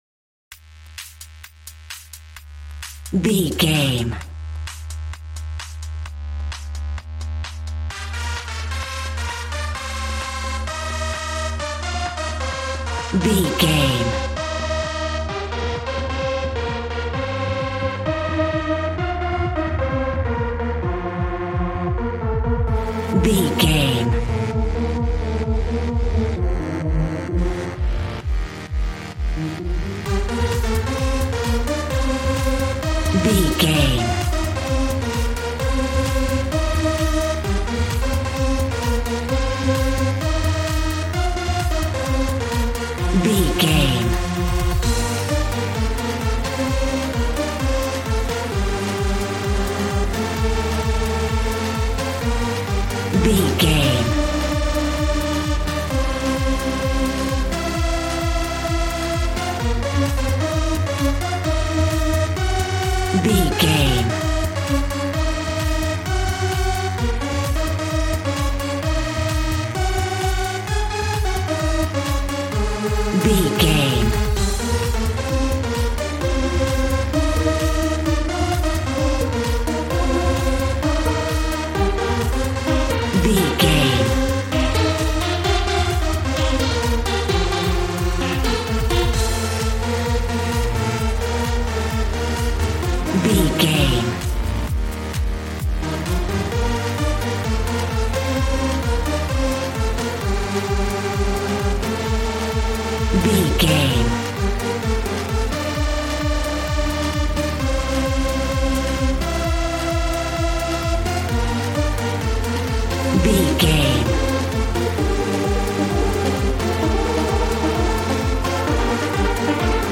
Aeolian/Minor
Fast
uplifting
lively
groovy
synthesiser
drums